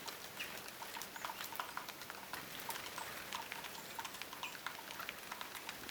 tuollaisia ääniä jollain tiaisella,
ehkä sinitiaislinnulla,
muistuttaa hieman peipon lentoääntä?
tuollaisia_aania_jollakin_tiaisella_ehkapa_sinitiaislinnulla_hiukan_muistuttaa_peipon_aanta_ehkapa.mp3